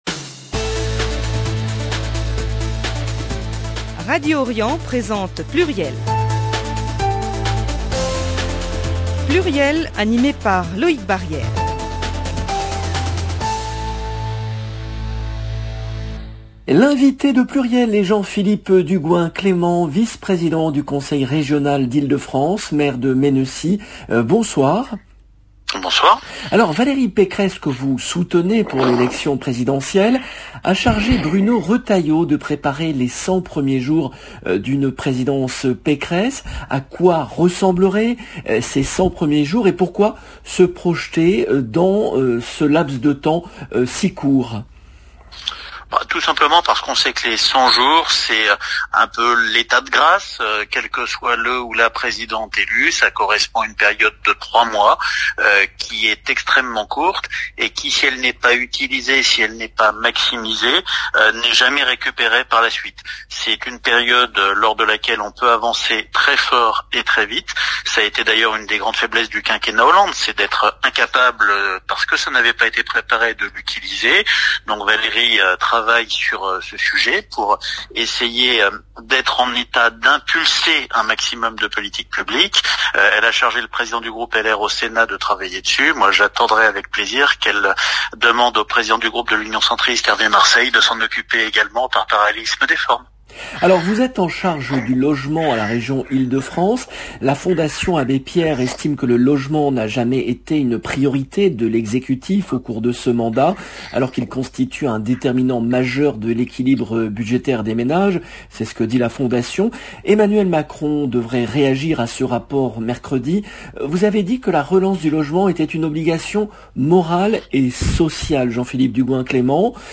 L’invité de PLURIEL est Jean-Philippe Dugoin-Clément , vice-président du Conseil régional d’Ile-de-France, maire UDI de Mennecy